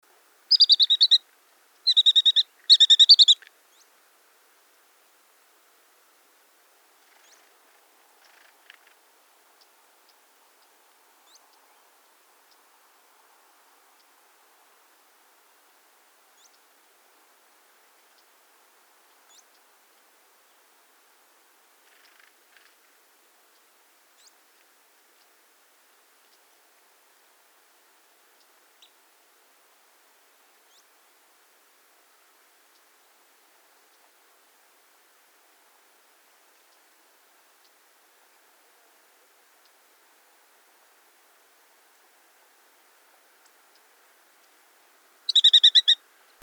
Monterita Canela Coludito Cola Negra